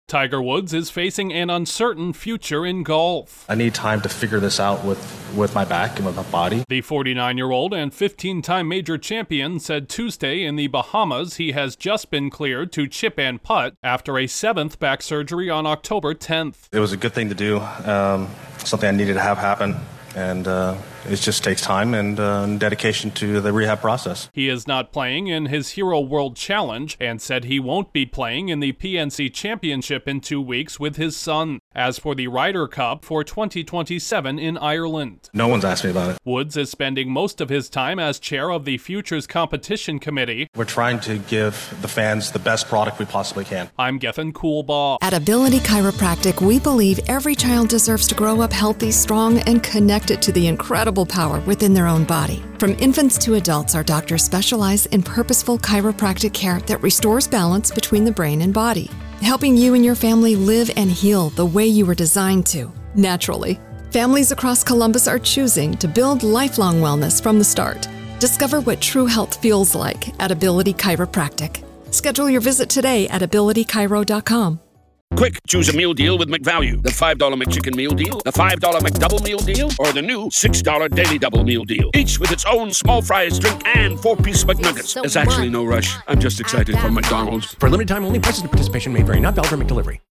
Golf’s most recognizable player remains in limbo over his return to the game. Correspondent